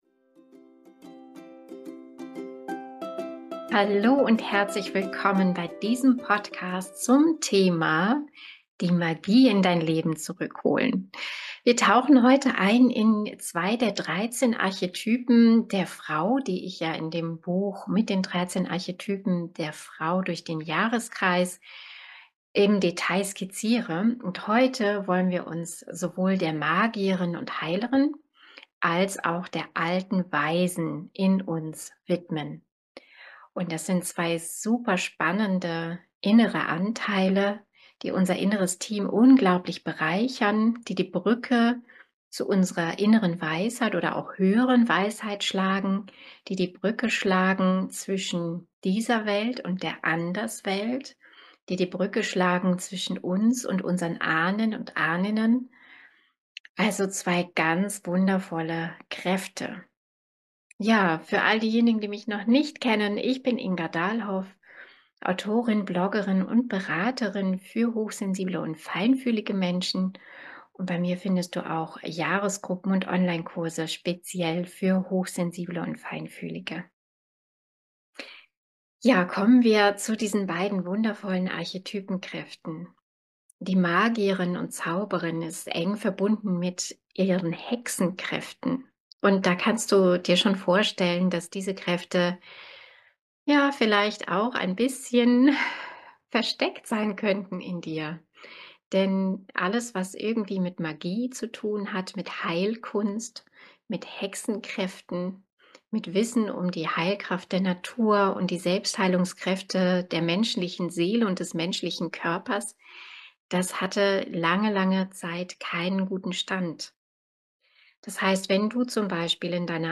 Erwecke deine innere Magierin / Heilerin und deine innere Alte Weise. Podcast mit einer Meditationsreise zu deiner inneren Magierin.